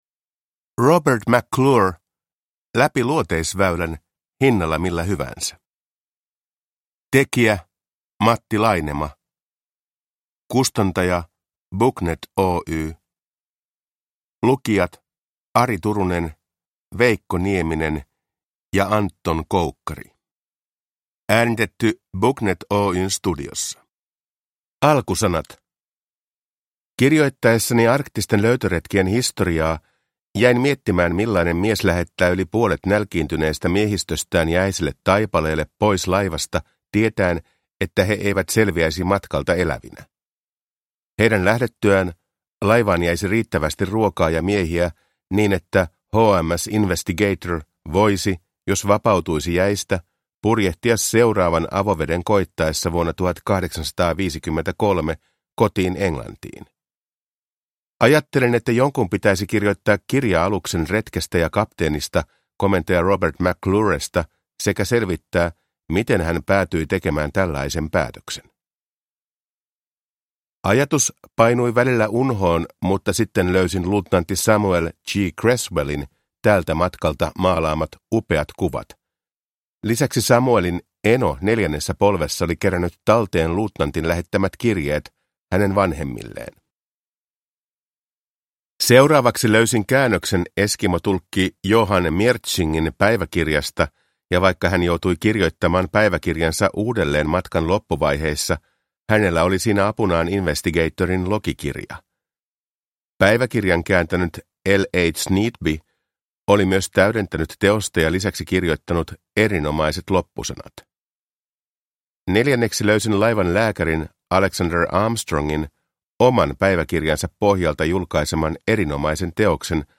Robert McClure – Ljudbok